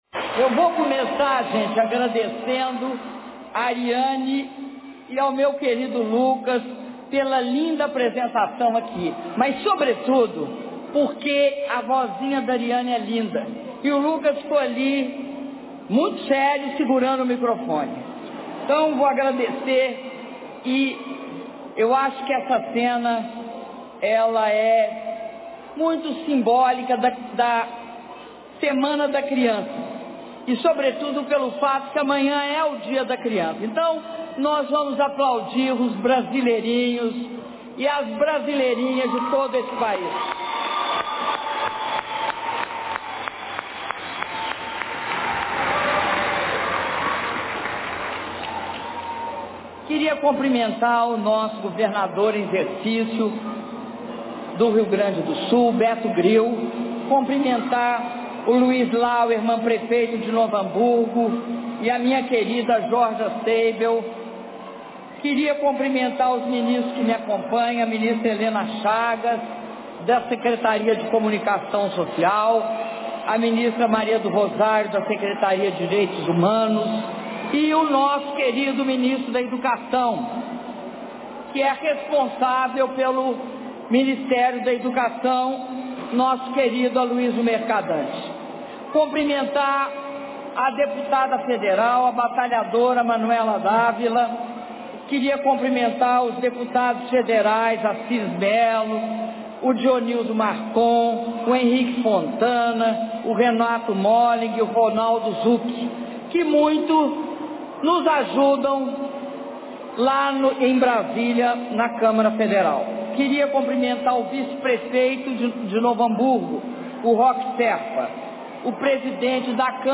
Áudio do discurso da Presidenta da República, Dilma Rousseff, na cerimônia de inauguração de Unidades de Educação Infantil - Novo Hamburgo/RS (16min11s)